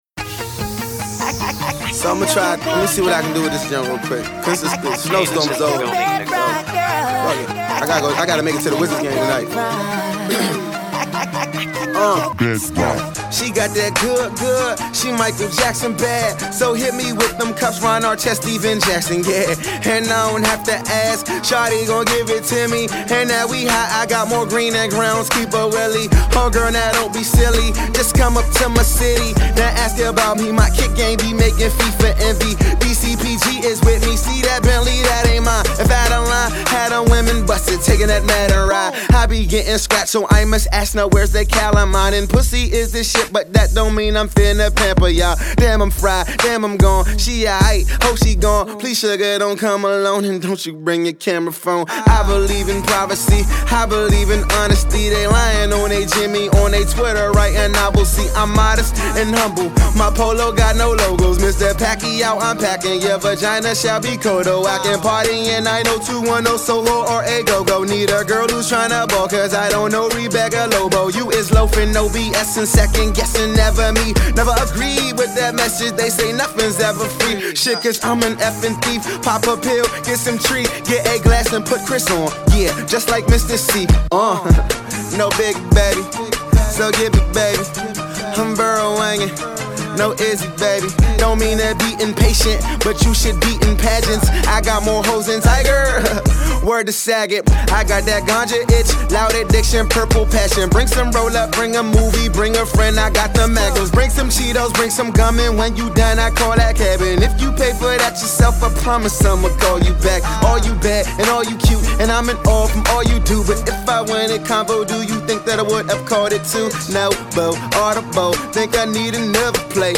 funky beat